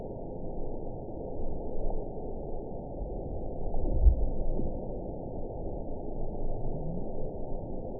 event 919884 date 01/27/24 time 22:01:58 GMT (1 year, 3 months ago) score 9.32 location TSS-AB07 detected by nrw target species NRW annotations +NRW Spectrogram: Frequency (kHz) vs. Time (s) audio not available .wav